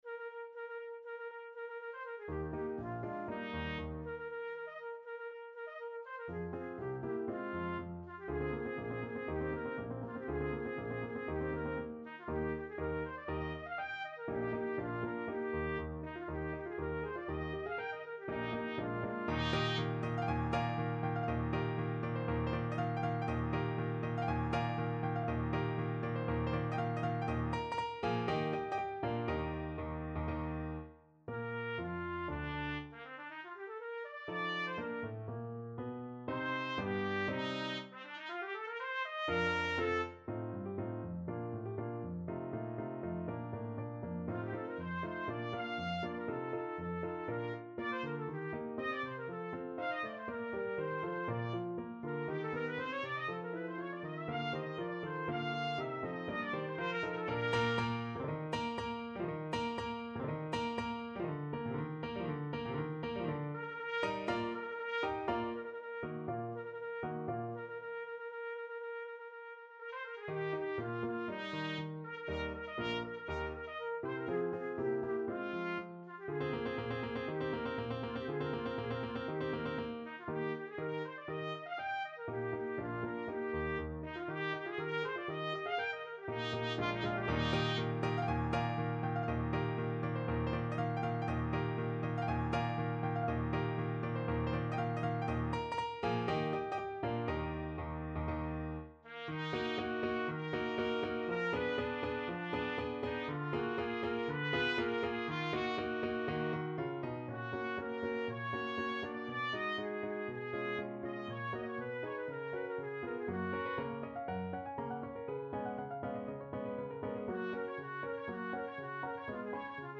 Rondo allegro
2/4 (View more 2/4 Music)
Trumpet  (View more Advanced Trumpet Music)
Classical (View more Classical Trumpet Music)